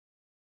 rat
eat_2.ogg